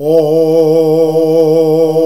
OOOOH  A#.wav